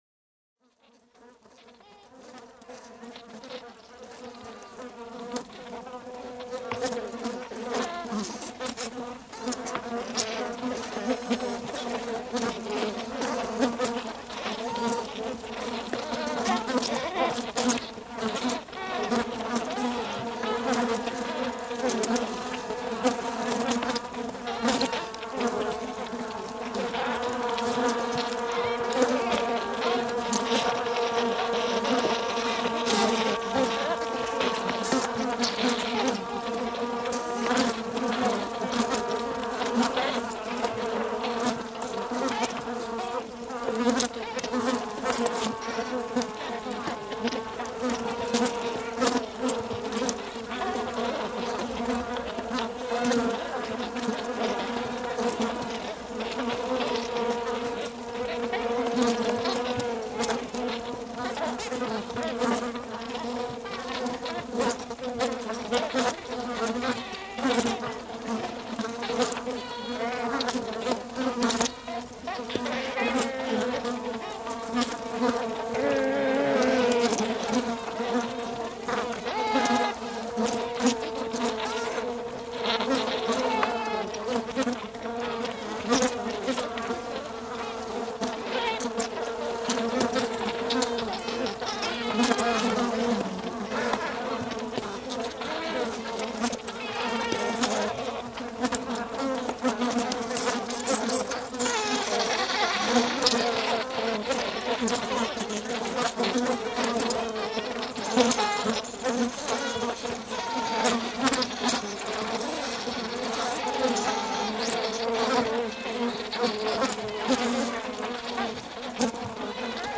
sound sculptures, weather, insects